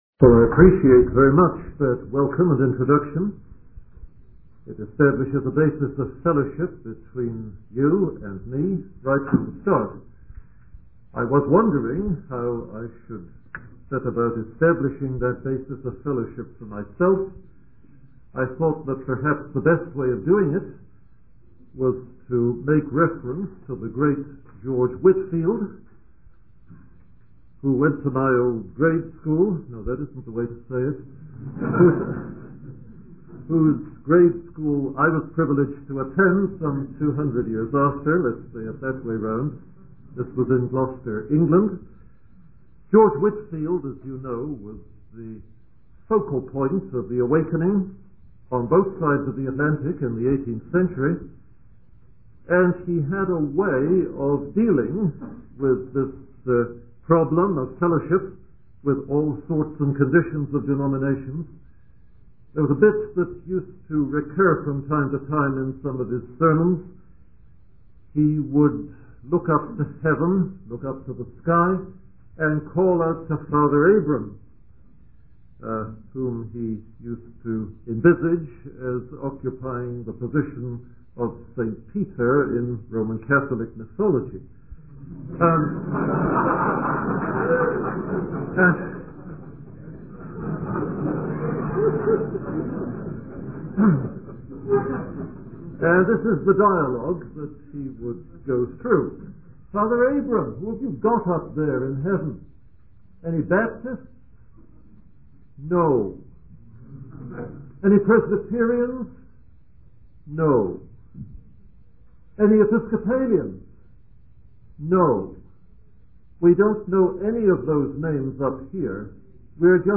In this sermon, the preacher discusses the impact of God's blessings on his people and the resulting evangelistic overflow.